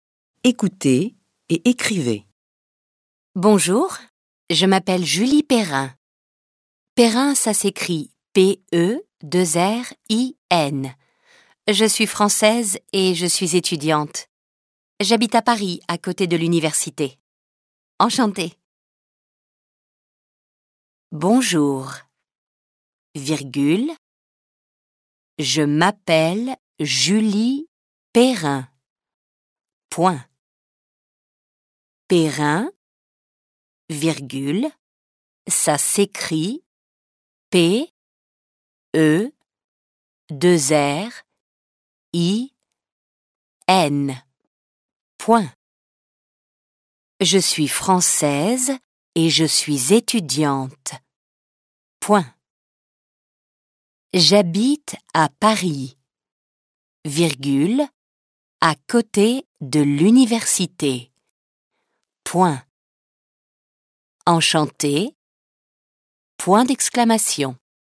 دیکته ، دیکته - مبتدی